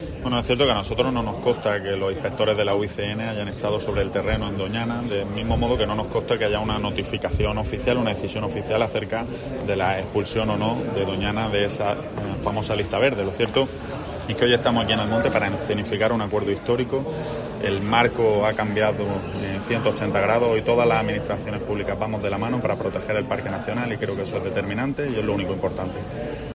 Ramón Fernández-Pacheco, portavoz del Gobierno Andaluz